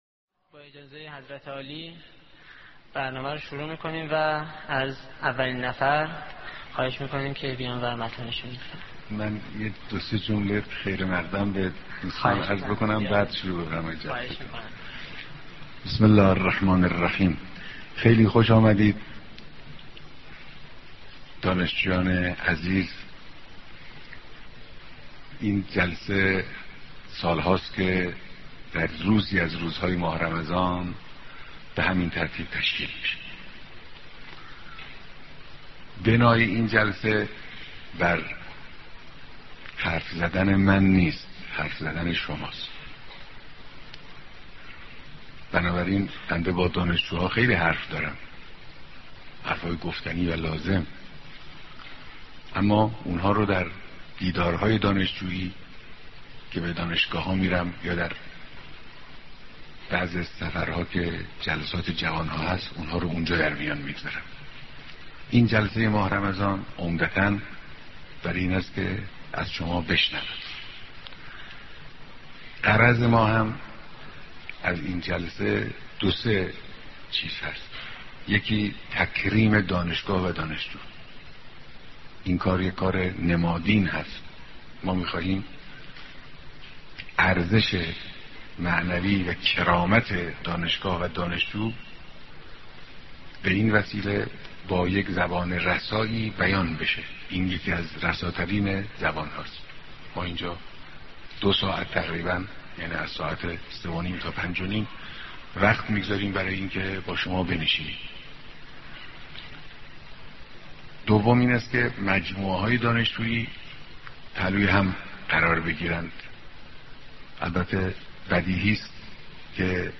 بيانات رهبر معظم انقلاب اسلامى در ديدار دانشجويان در ماه مبارك رمضان